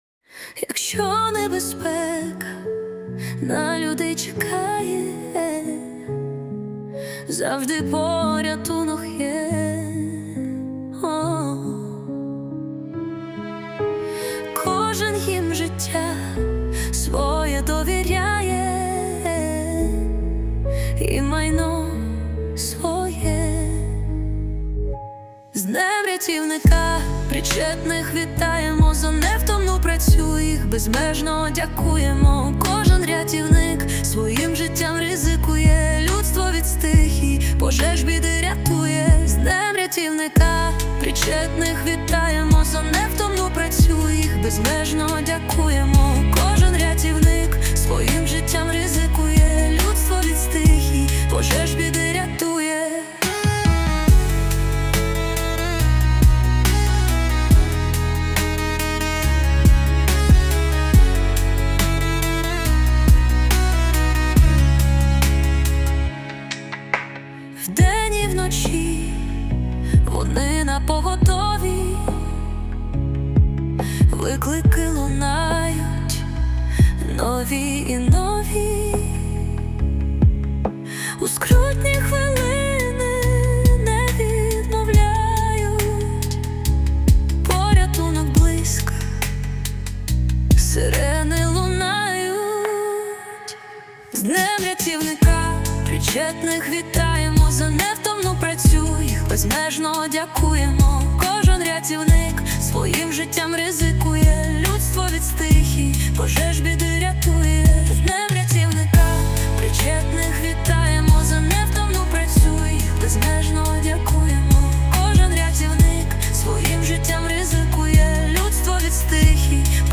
ТИП: Пісня